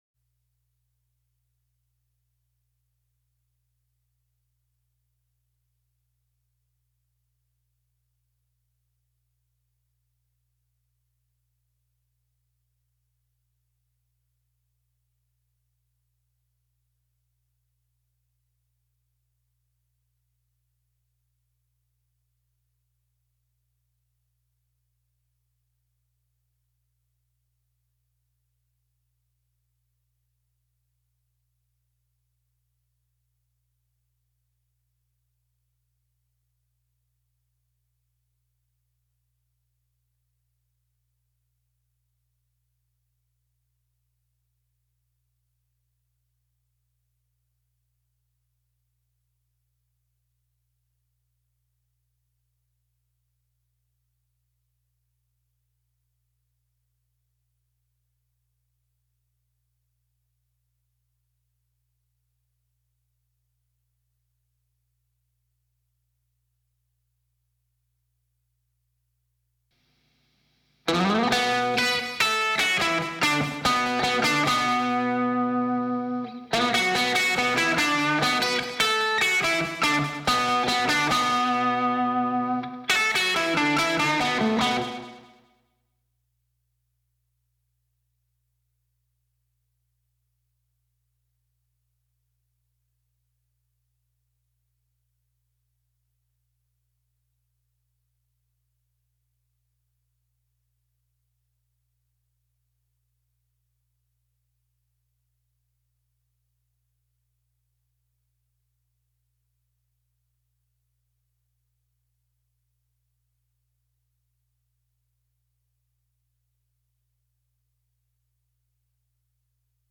alto sax
guitar
keyboard
drums
bass, backing voc, percussion
tenor sax
trumpet
baritone sax